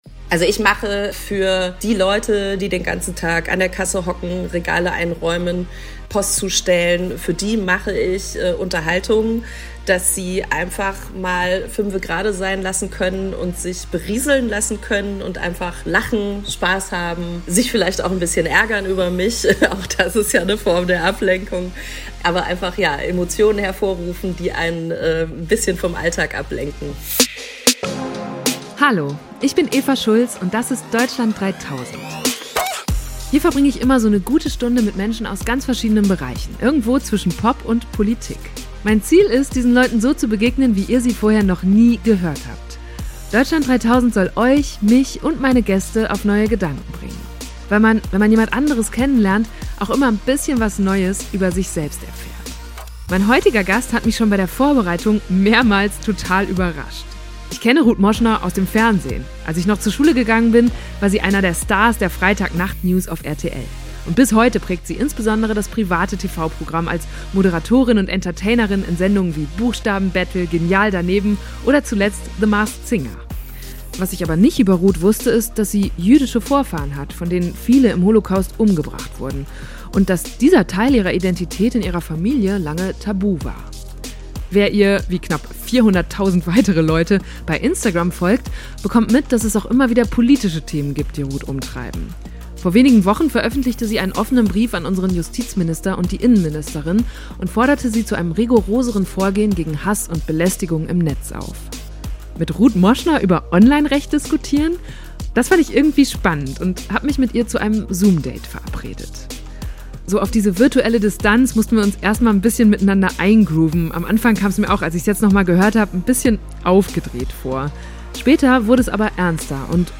Das fand ich irgendwie spannend, und habe mich mit ihr zu einem Zoom-Date verabredet. So auf die virtuelle Distanz mussten wir uns erstmal ein bisschen miteinander eingrooven, am Anfang kam es mir auch, als ich es nochmal gehört habe, ein bisschen aufgedreht vor. Später wurde es aber ernster, und Ruth hat mich noch mal überrascht – zum Beispiel damit, dass sie sich heute durchaus wieder von Stefan Raab in den Ausschnitt fassen lassen würde.